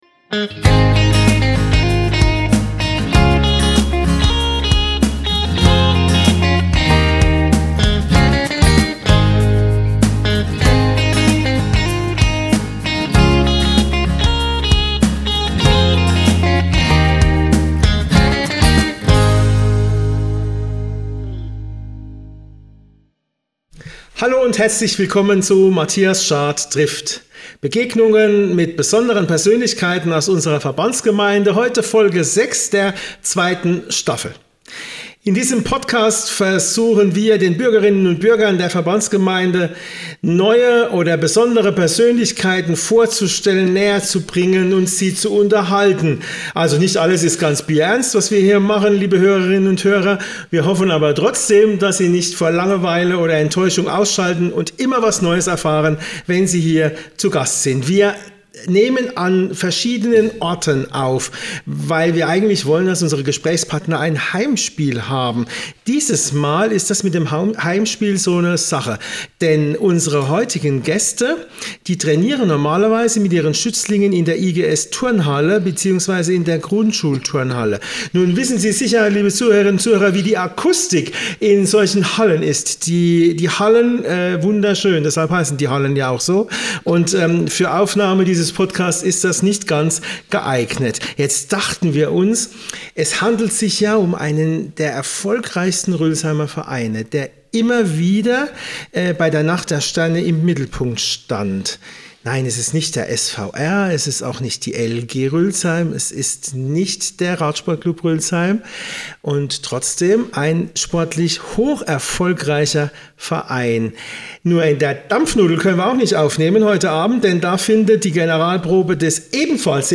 Im CKK in Rülzheim sprechen die vier über die Unterschiede zwischen Karate und anderen Kampfsportarten, die Erfolge des Vereins bei Wettbewerben, Selbstverteidigung und Selbstbehauptung und vieles mehr.